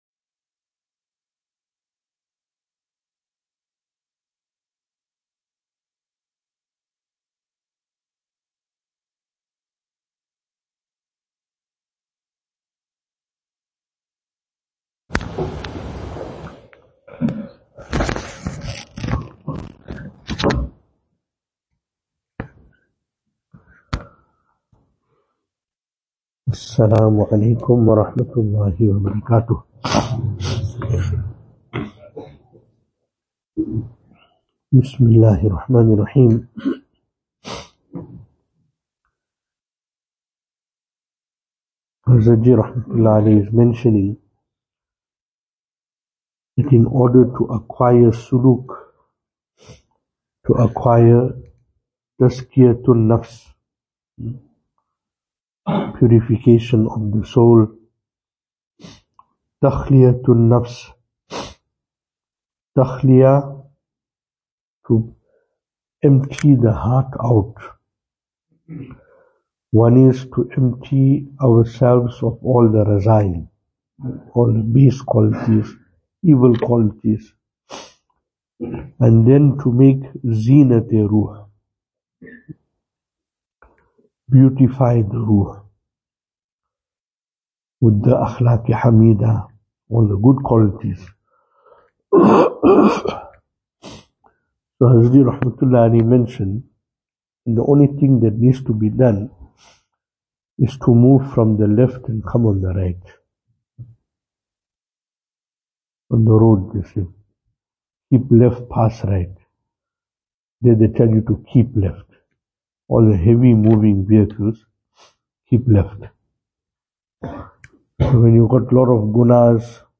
Venue: Albert Falls , Madressa Isha'atul Haq Service Type: Zikr